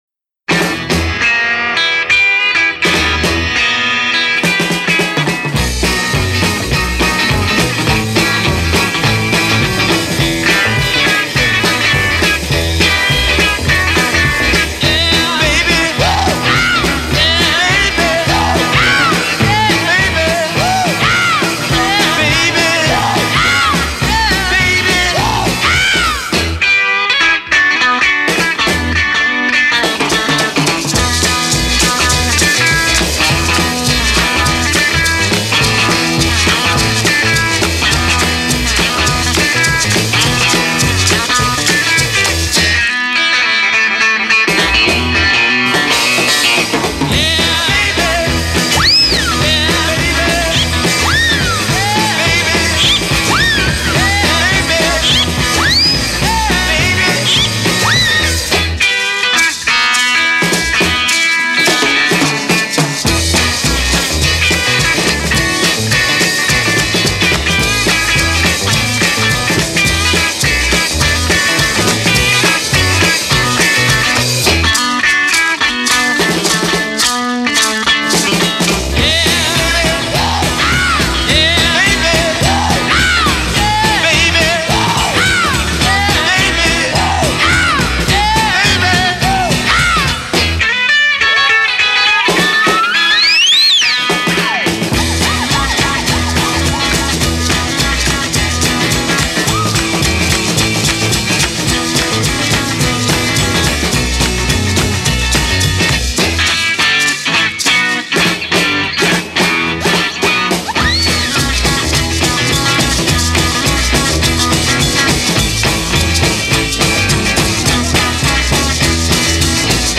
Original Mono